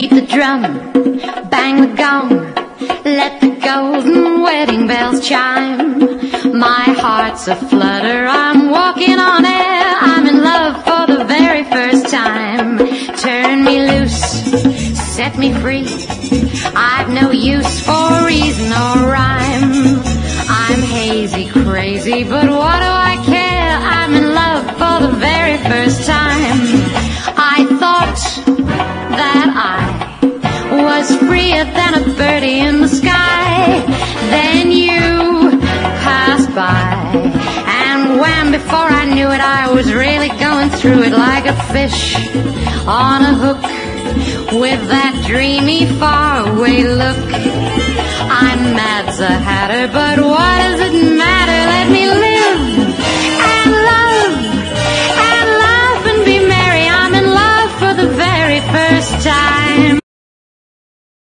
SOUL / SOUL / 70'S～ / DISCO / DANCE CLASSIC / DRUM BREAK
フロア・ユースな燃えるディスコ・ファンク！
フロア仕様のナイス・ファンキー・ディスコを4曲収録！